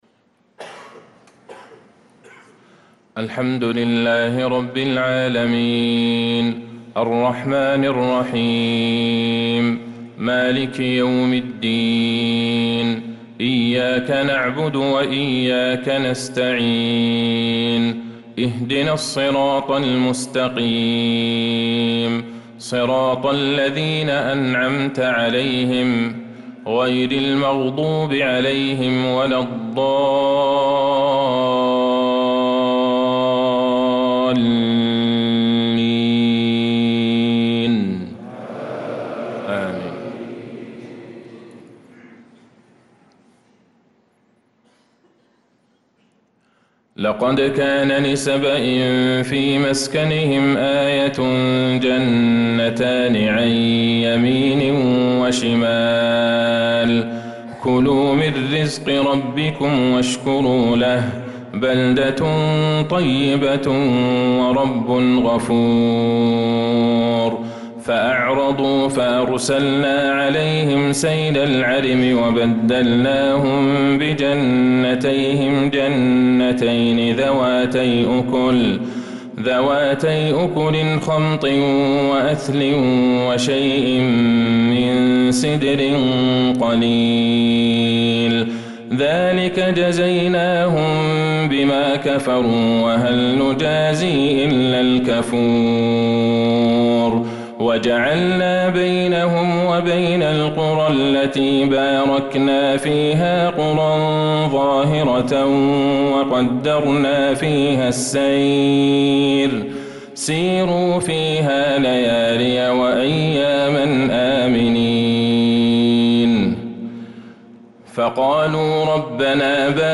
صلاة العشاء للقارئ عبدالله البعيجان 18 رجب 1446 هـ